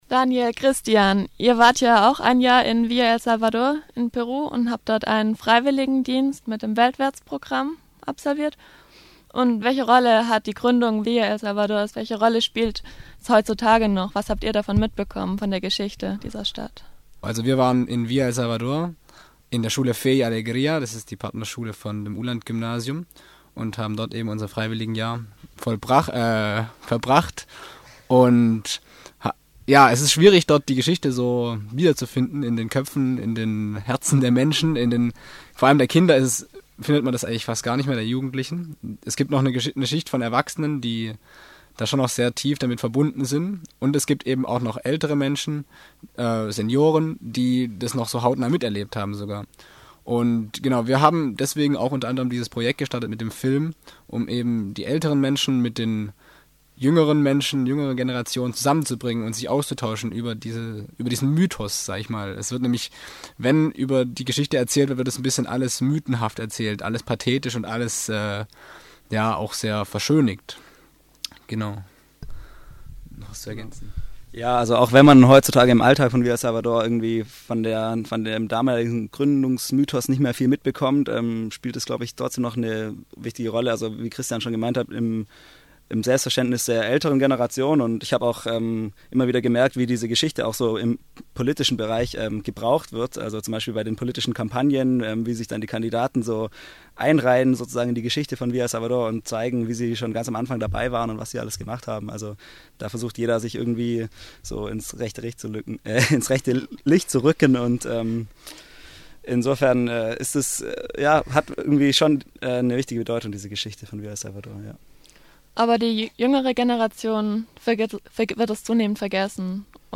im Studio